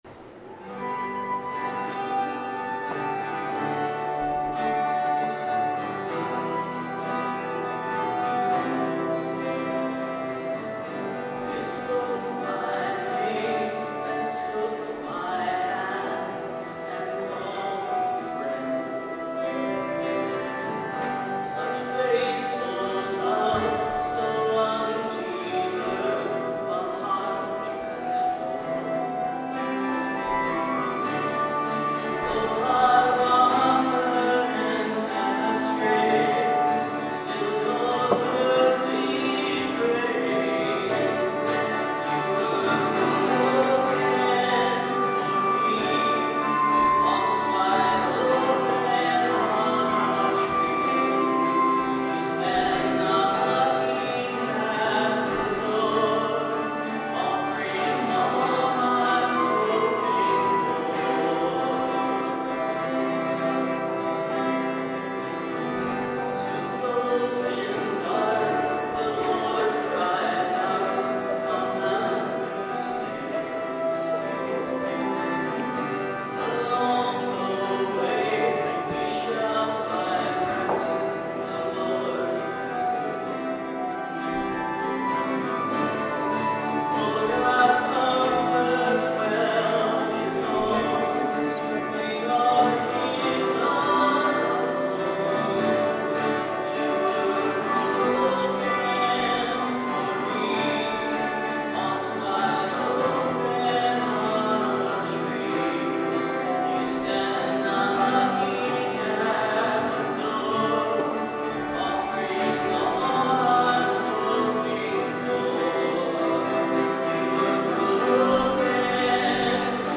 11/03/13 Mass Recording of Music